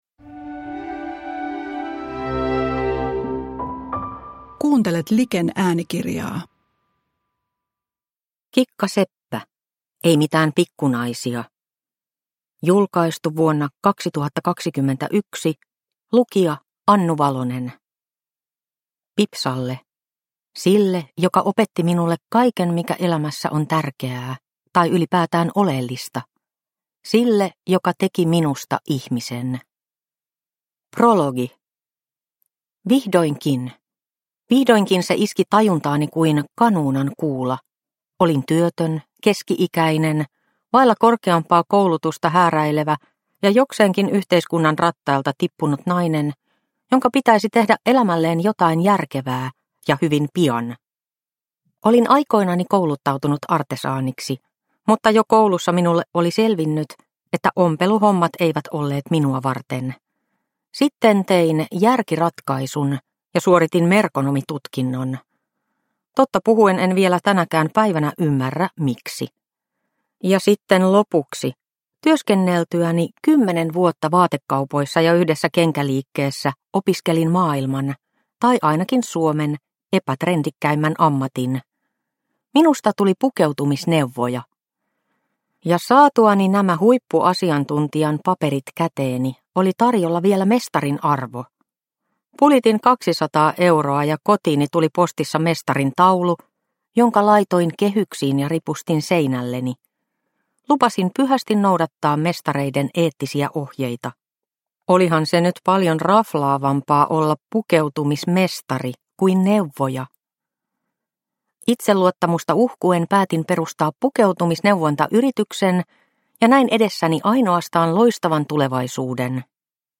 Ei mitään pikku naisia – Ljudbok – Laddas ner